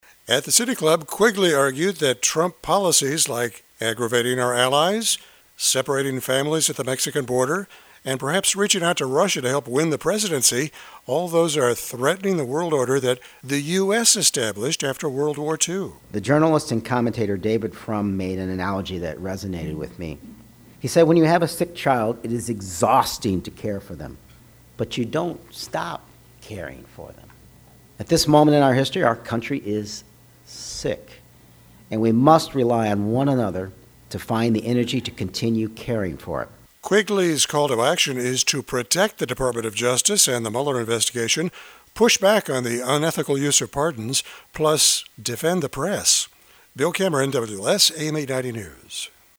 At the City Club, Quigley argued that Trump policies like aggravating our allies, separating families at the Mexican border and perhaps reaching out to Russia to help win the presidency are threatening the world order that the U.S. established after World War II.